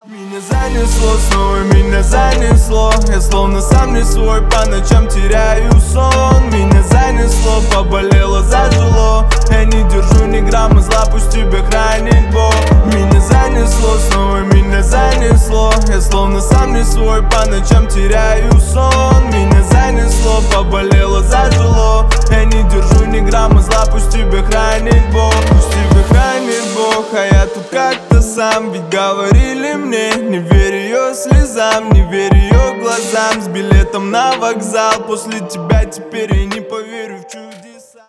• Качество: 320 kbps, Stereo
Рэп и Хип Хоп
грустные